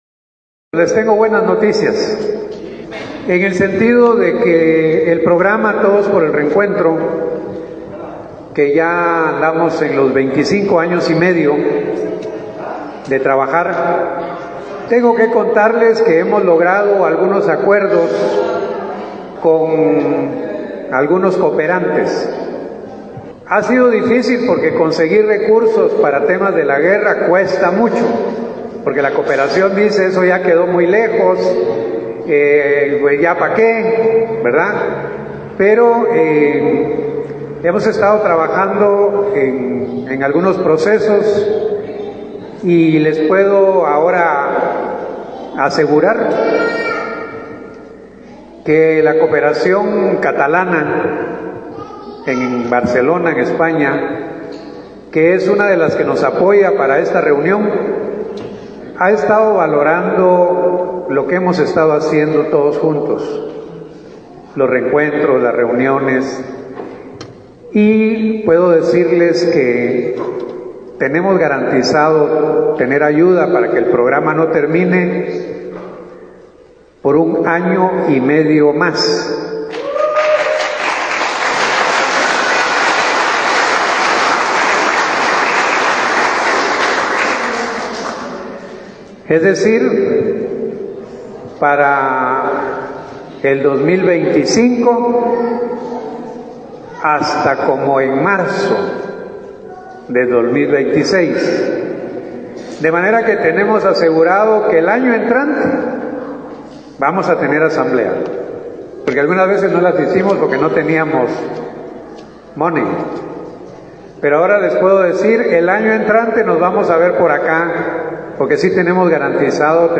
El anuncio fue realizado ante los familiares de niñas y niños desaparecidos por la guerra, quienes participaron en la Asamblea Nacional realizada en Santa Cruz, municipio del departamento de Alta Verapaz.